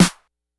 snr_59.wav